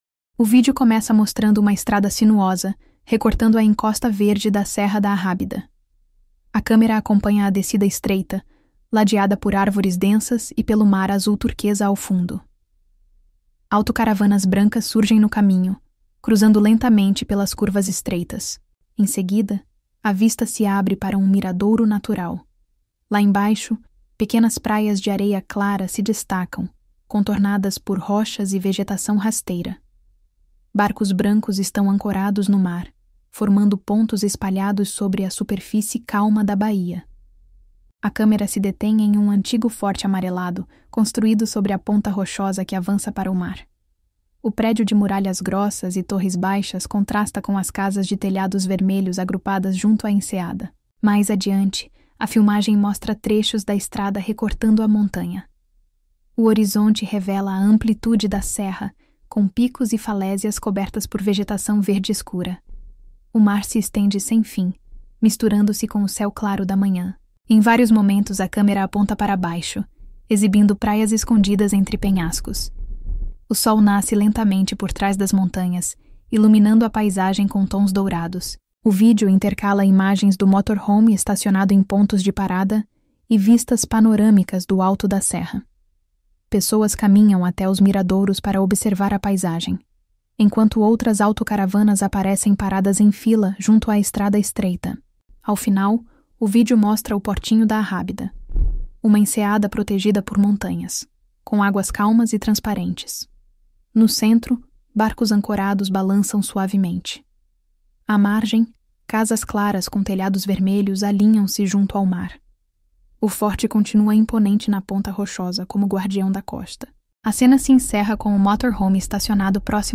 Áudio-descrição — resumo acessível do vídeo original (25/08/2025).